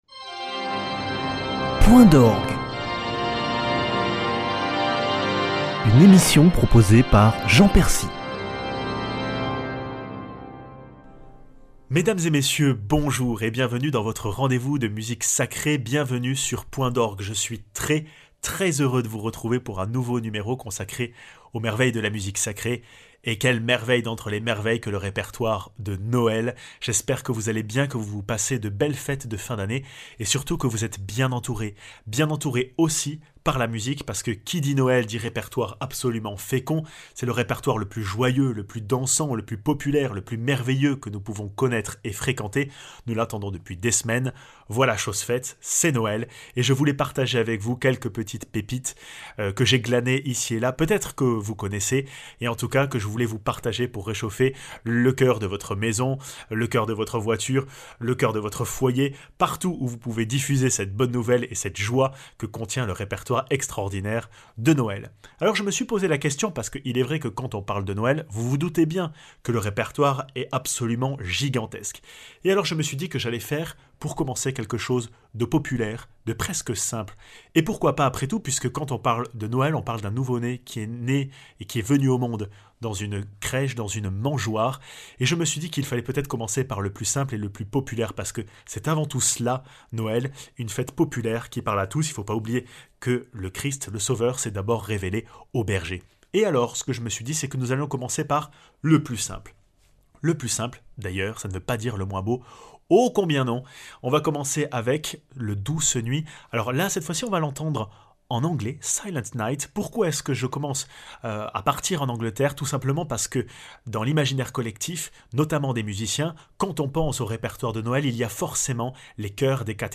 De la nuit douce et mystérieuse à l’éclatement de joie des trompettes, écoutez l’histoire de la nuit de Noël en musique (Silent night de Lumsden, O magnum mysterium de Busto, oratorio de Noël de Bach)